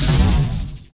lightningimpact.wav